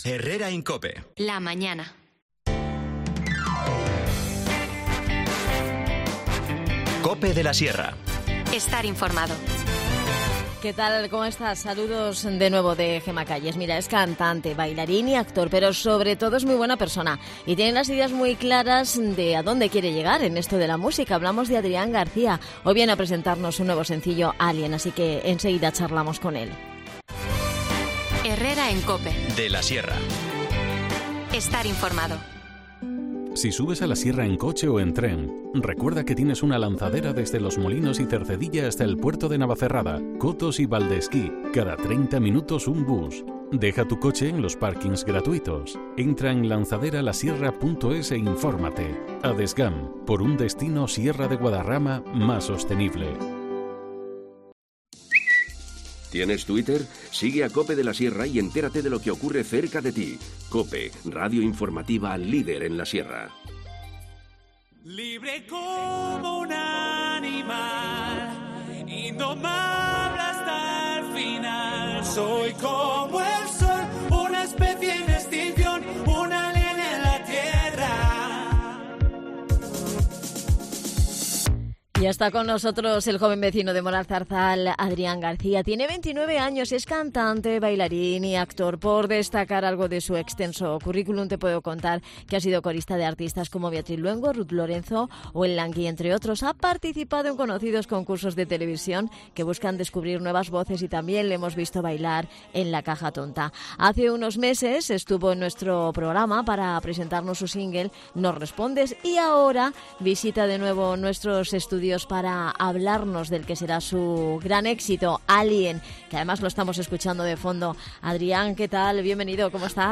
Si quieres escucharle, no te pierdas esta entrevista.